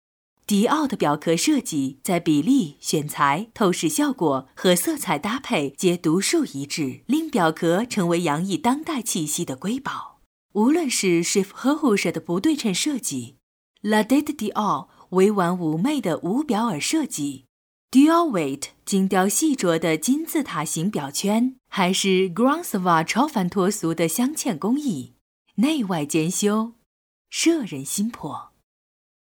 • 6央视女声1号
品牌广告-亲和魅力